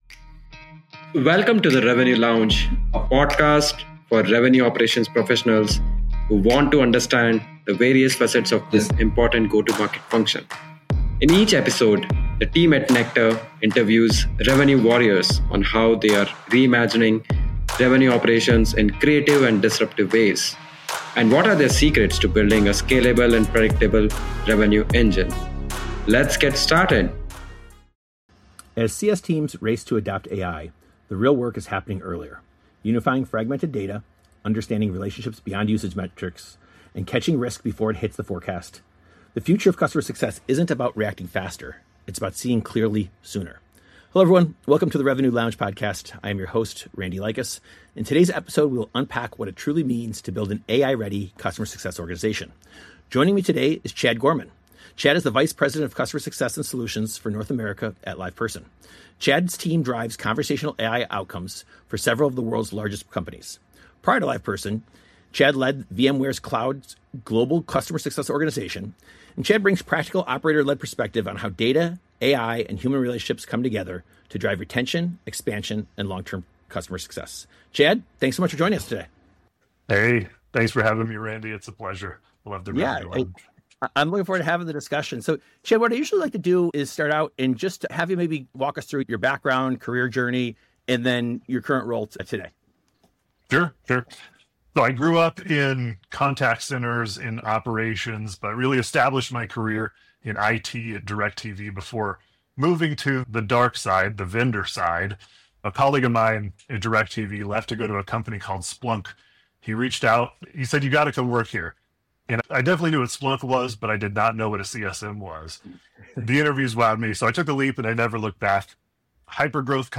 The Revenue Lounge podcast is a series of candid conversations with leaders in the revenue operations space.